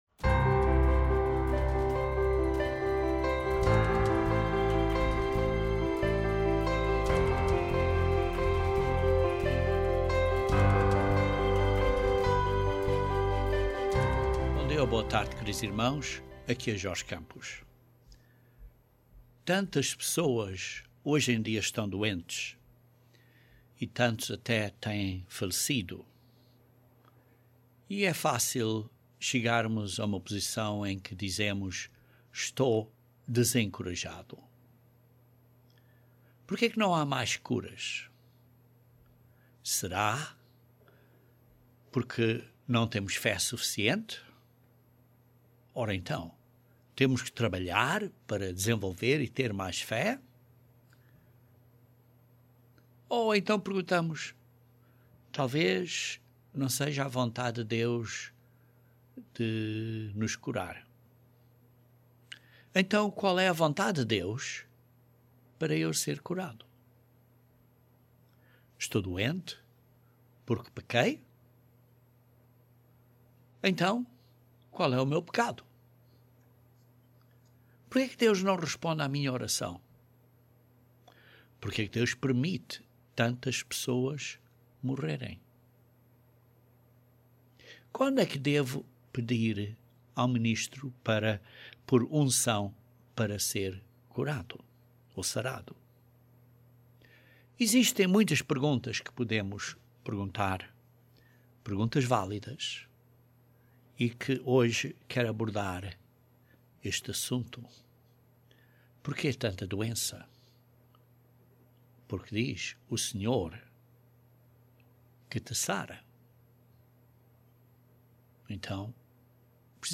Então qual é a vontade de Deus para que eu venha a ser curado? Este assunto importante é abordado neste sermão e a nossa responsibilidade pessoal é discutida.